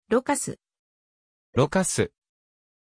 Aussprache von Loucas
pronunciation-loucas-ja.mp3